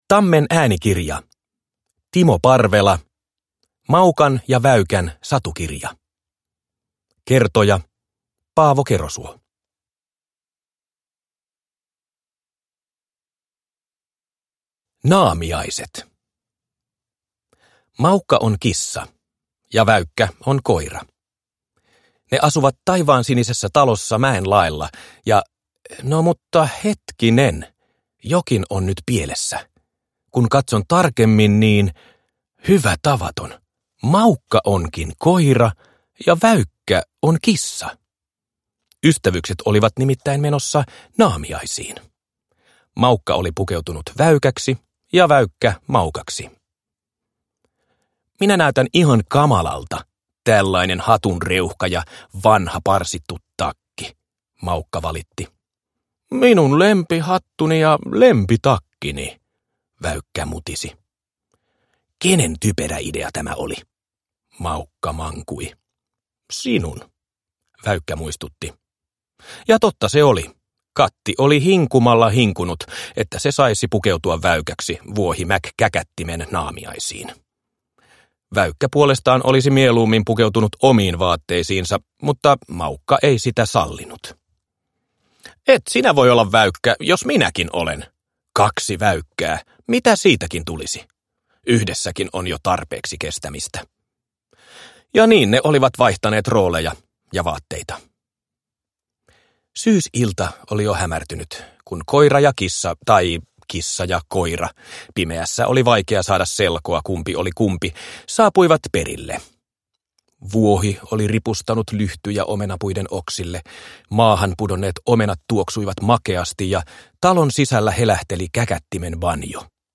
Maukan ja Väykän satukirja – Ljudbok – Laddas ner